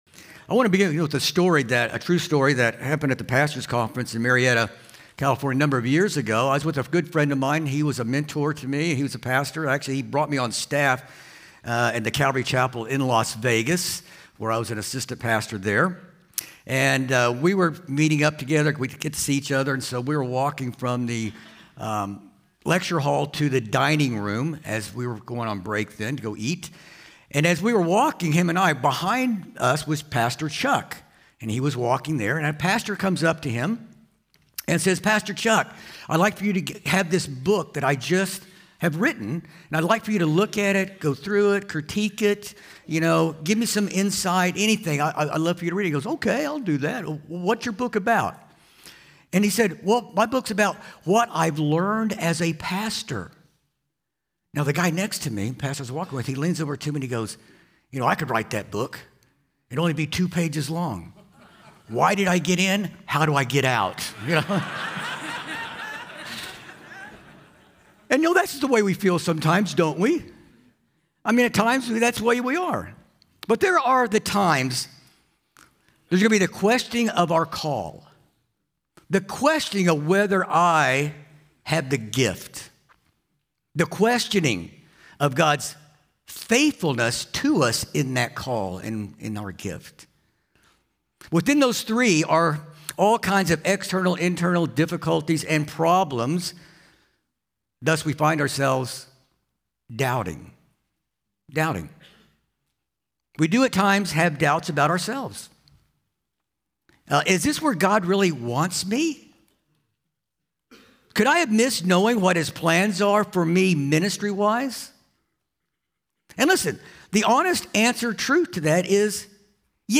Home » Sermons » “The Doubting Pastor”